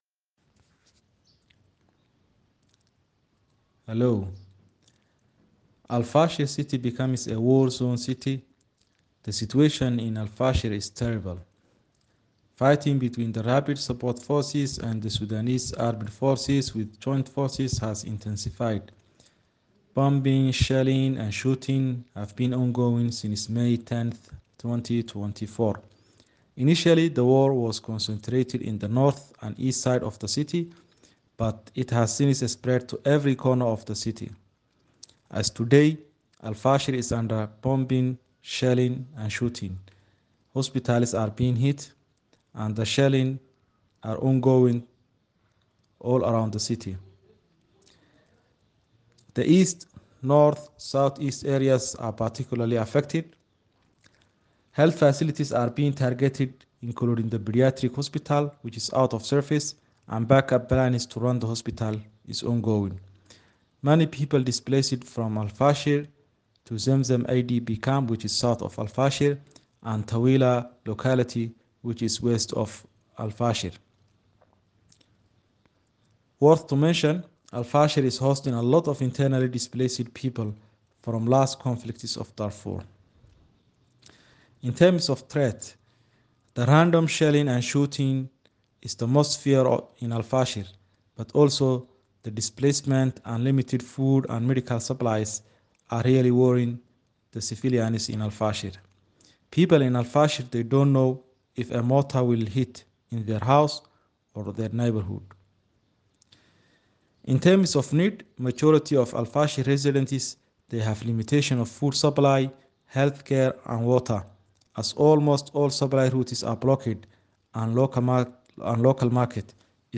Sudan: Voice note and Update from El Fasher